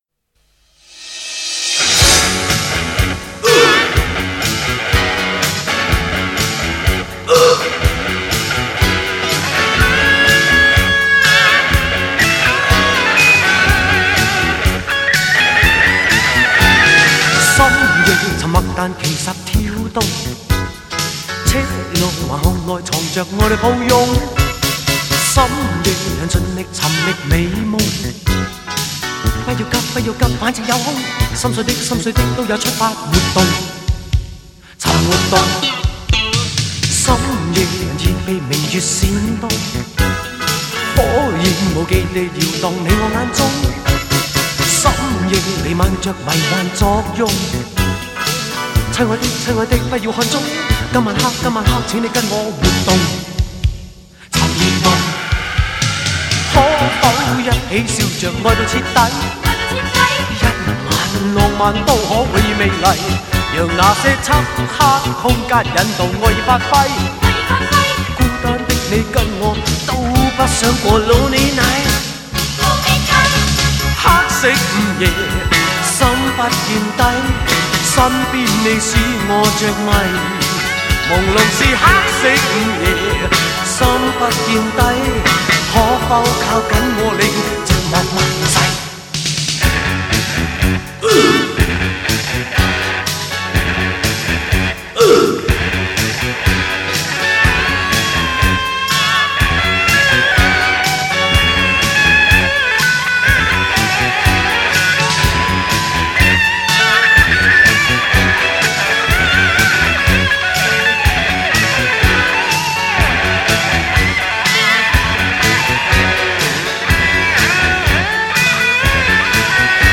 都是节拍强劲的歌曲